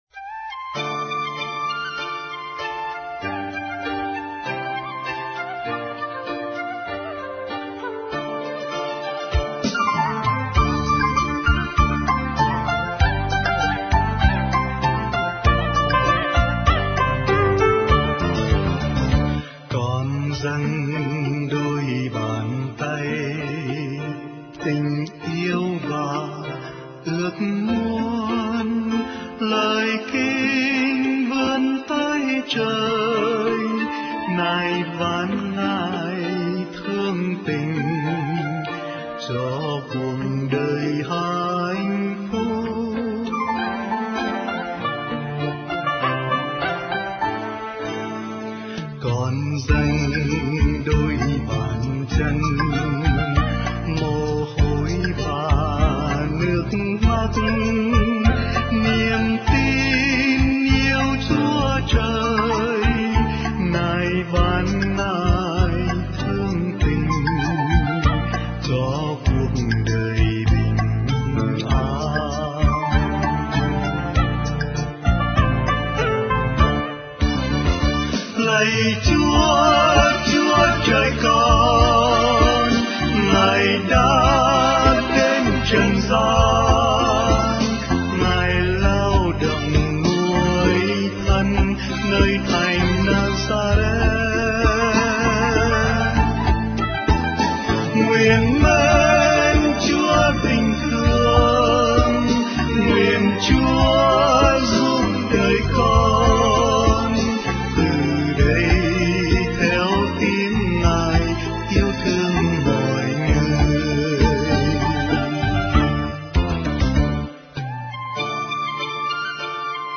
Dòng nhạc : Ngợi ca Thiên Chúa